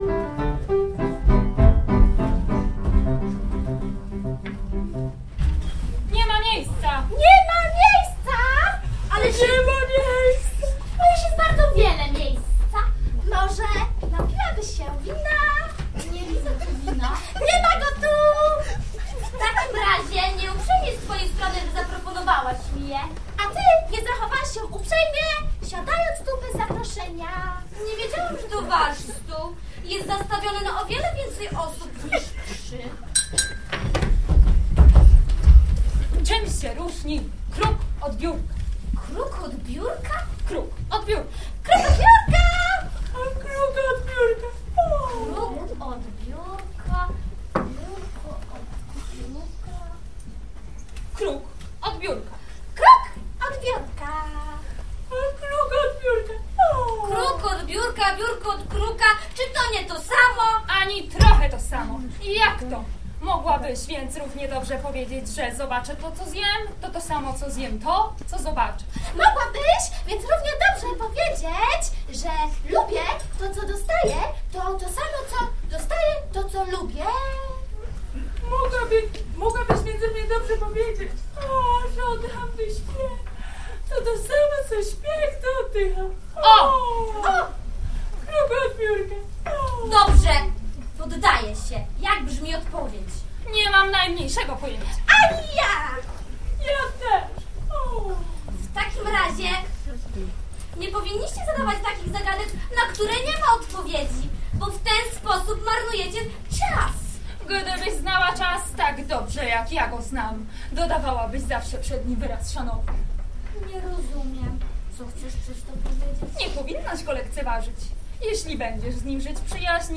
Studencki Teatr PWSZ w Lesznie „Leżak”
29 kwietnia 2008, CKiS w Lesznie
musical dla dzieci i dorosłych wg.